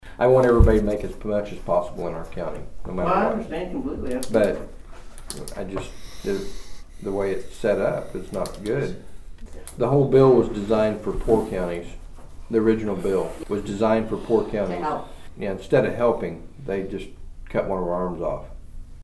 Here is Friddle voicing his frustration.